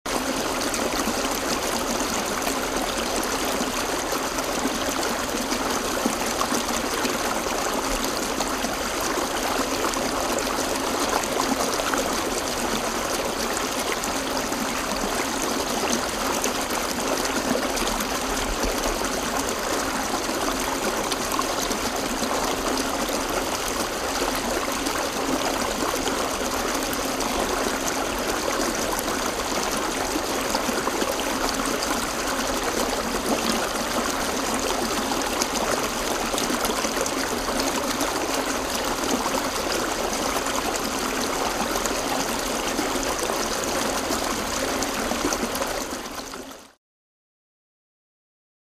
Wave Tunnel; Interior Water Flow